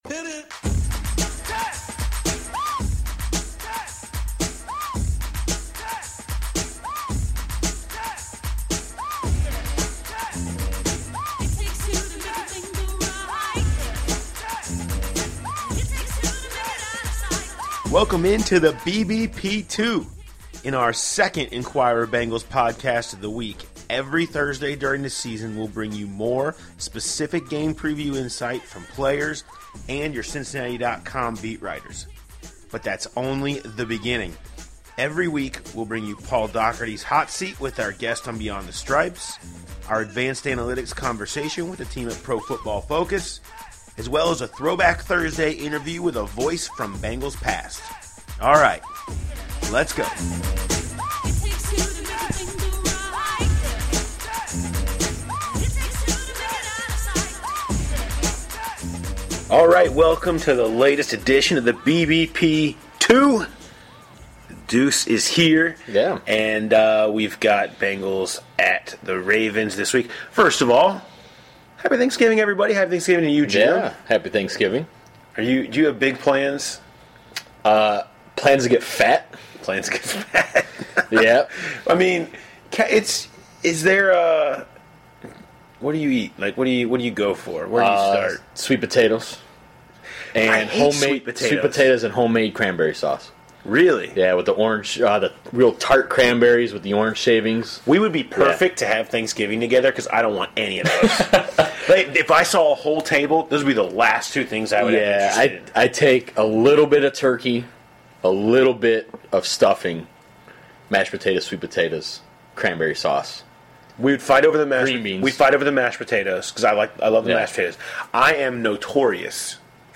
Plus, hear from Pro Football Focus on the improved Ravens defense up the middle, Brandon LaFell from Beyond The Stripes and the entertaining conference call with Baltimore's Steve Smith Sr.